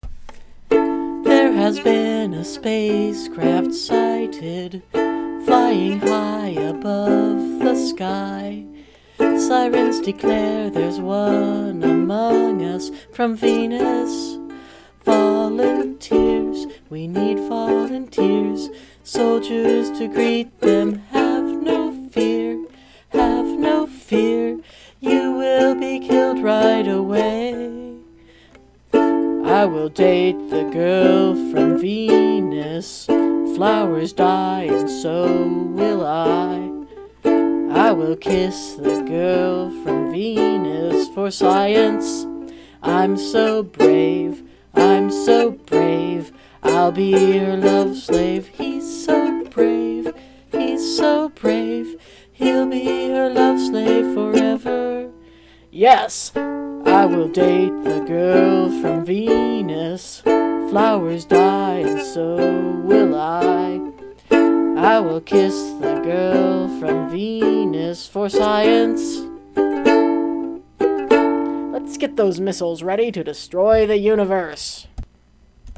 ukulele crushing power
Your show of range is wonderful.